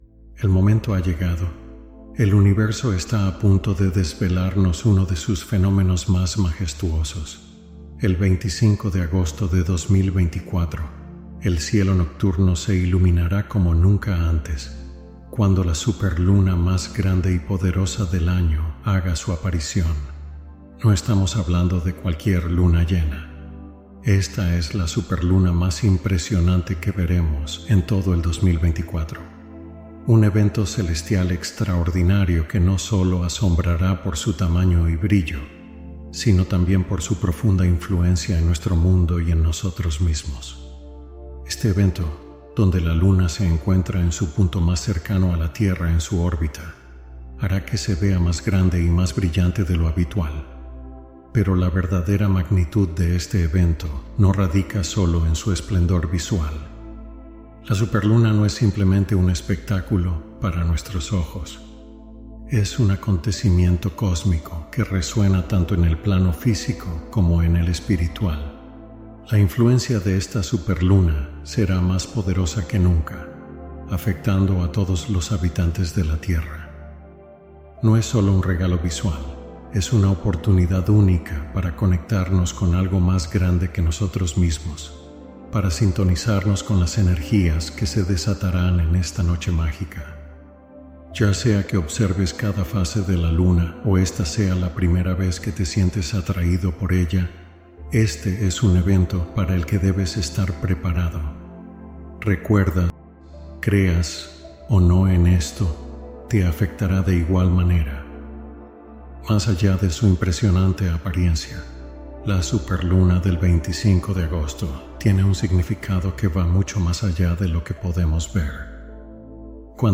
Práctica meditativa inspirada en ciclos de expansión interna